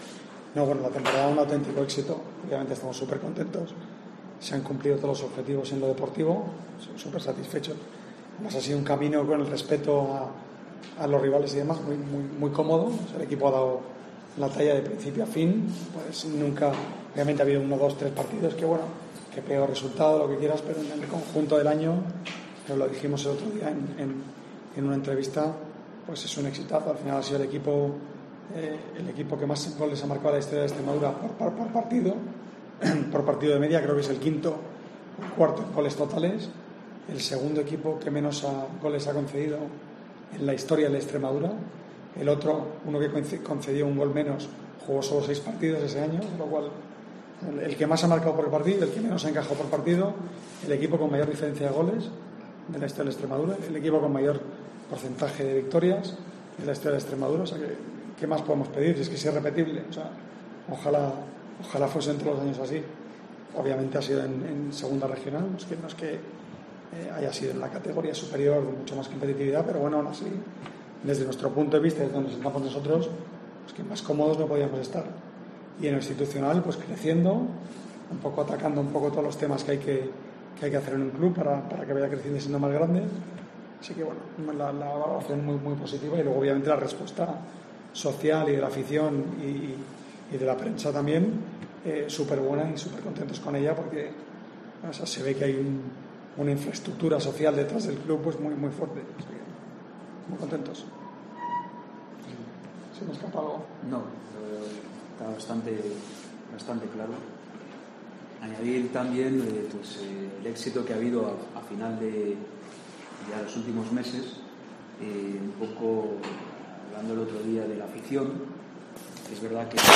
han comparecido en rueda de prensa para...